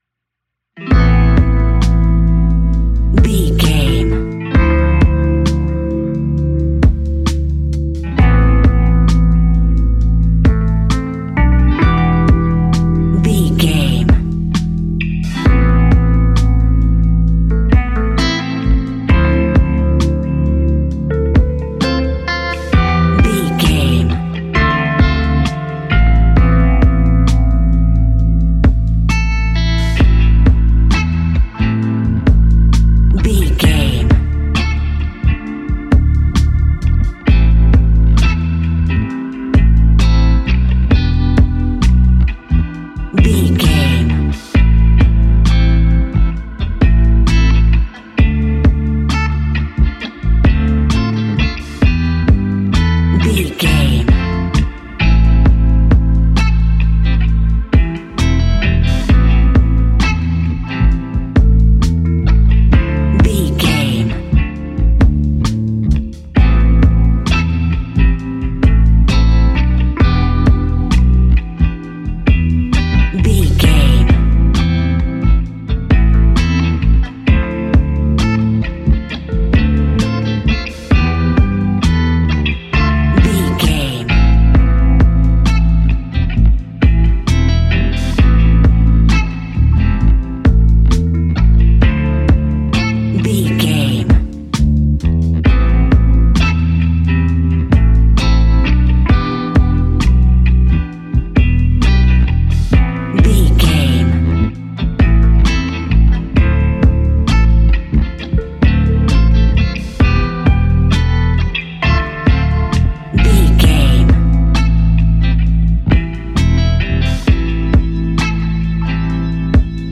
Ionian/Major
A♭
laid back
Lounge
sparse
new age
chilled electronica
ambient
atmospheric